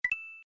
normal-coin.mp3